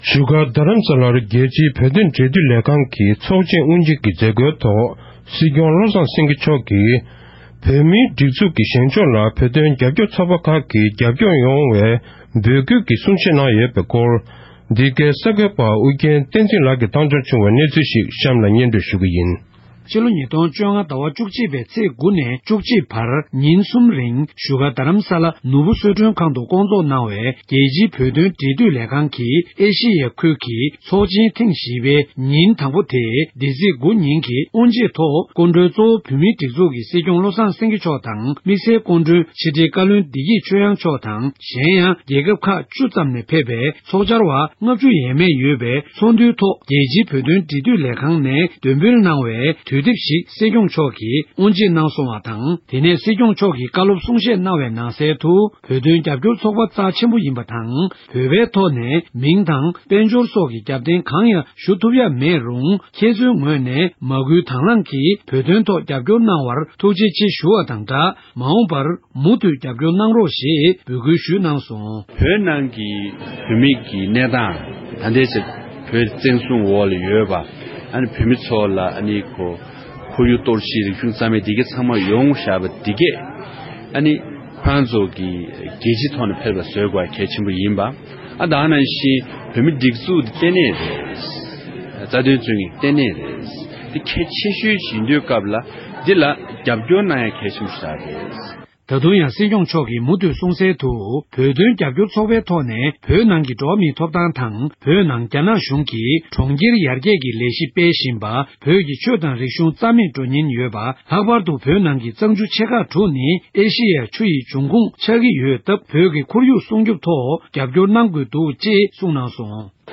གནས་ཚུལ་ཕྱོགས་སྒྲིག་ཞུས་པར་གསན་རོགས༎